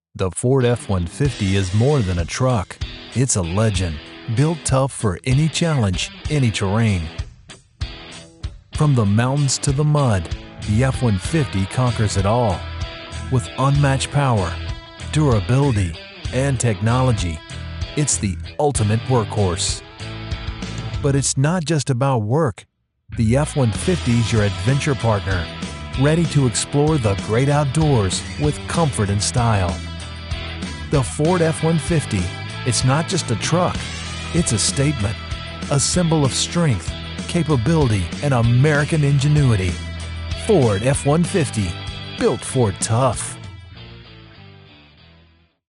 I can deliver a wide range of styles, from friendly and conversational to authoritative and dramatic.
Ford - F150. MANLY - FRIENDLY - INSPIRATIONAL - EVERYMAN .mp3